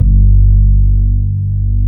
FRETLESSG2-R.wav